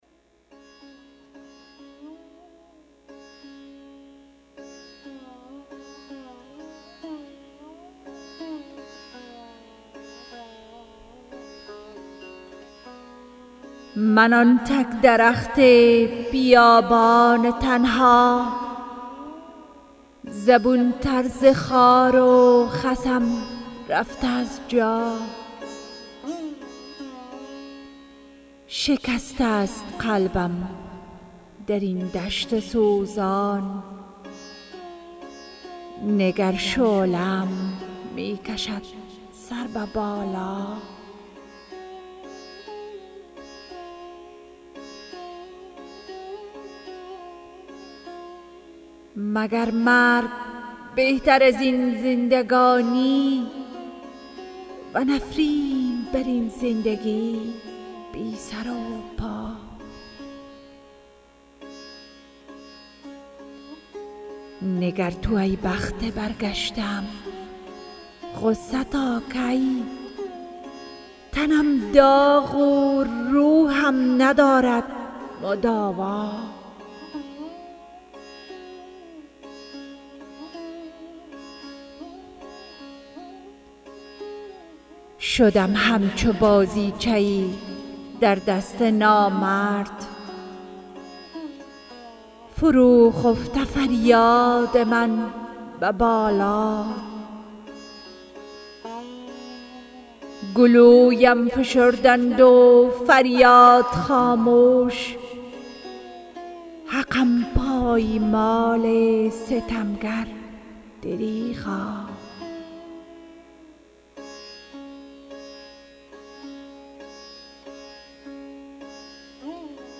ديکلمهً يک شعر زيبا
zan.mp3